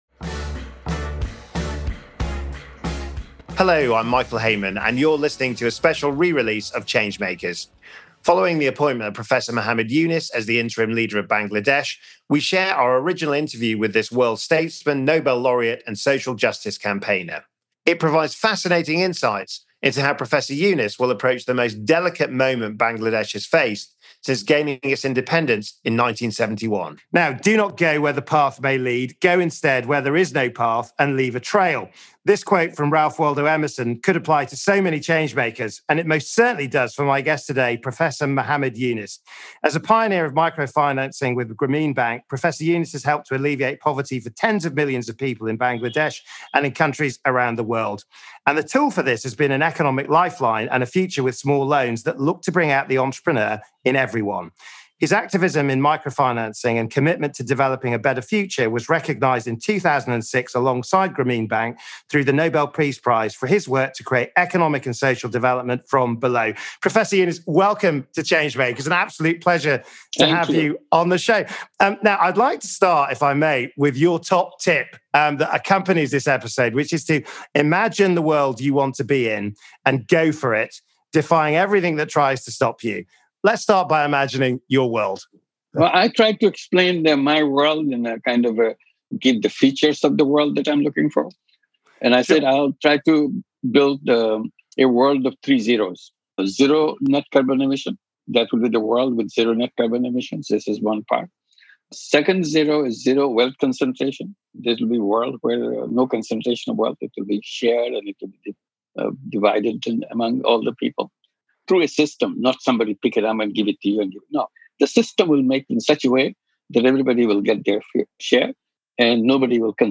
His ethos is driven by a view of human nature that is innately good, and a world view that critiques our economic structures as having suppressed people’s natural selflessness and creativity. This interview is a story of how when a big idea takes hold, the impossible really does become possible.